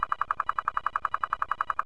They emit trains of short pulses that are 0.0001 seconds long.
LISTEN: Chinook
When these whale biosonar pulses are slowed down by 45 times, the difference between Chinook and coho salmon is clearly audible -- even to humans.